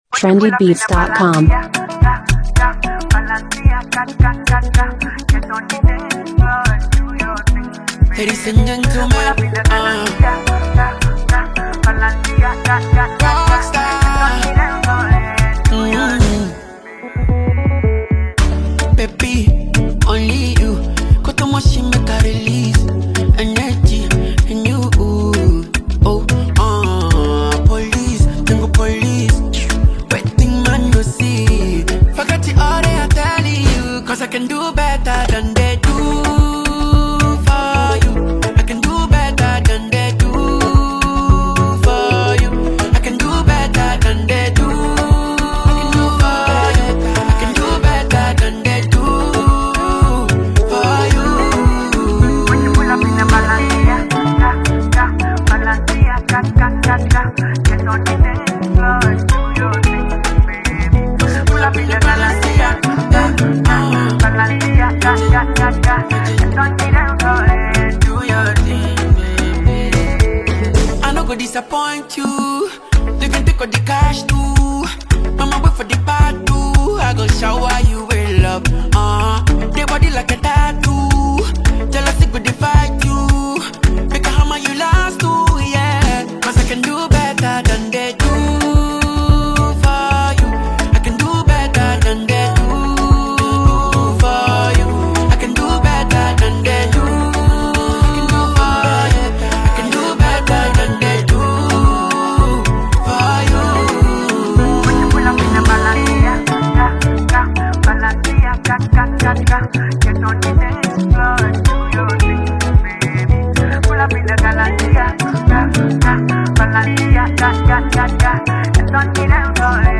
Ghana’s highlife and Afrobeat sensation
energetic rhythms and heartfelt lyrics